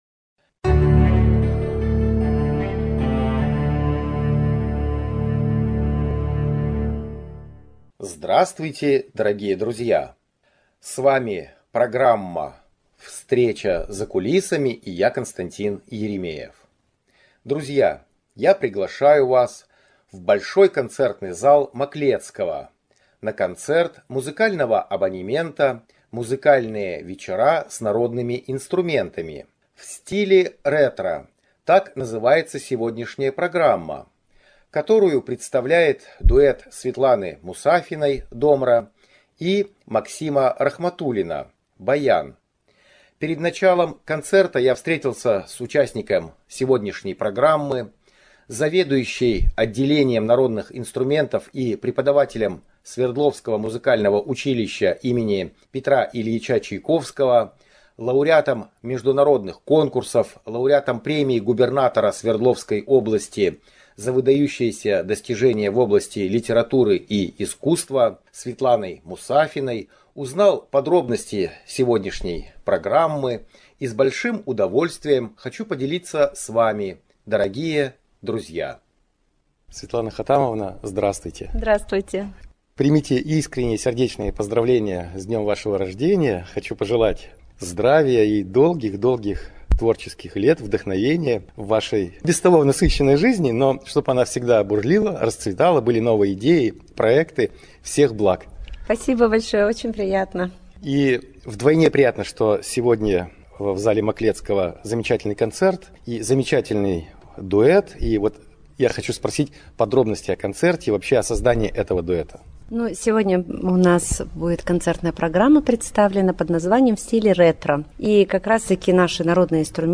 Большой зал Маклецкого. Концерт "В стиле ретро"